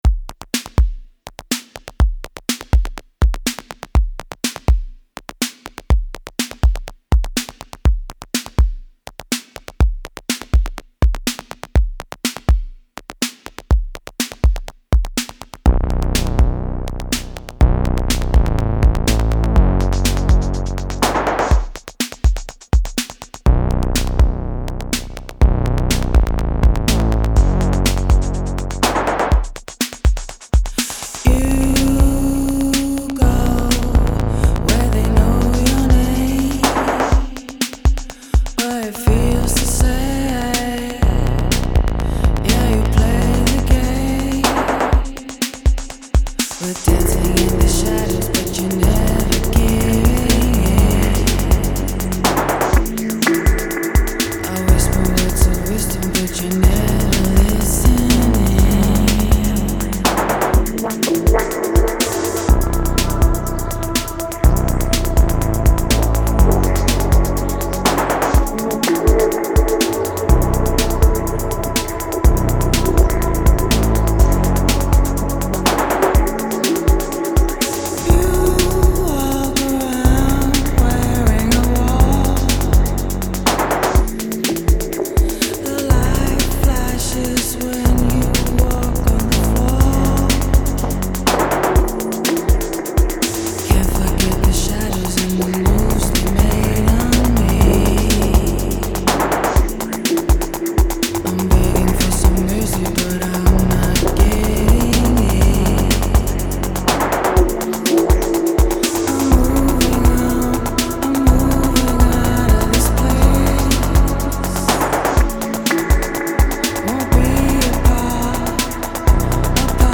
Genre: Chillout, Downtempo, Ambient.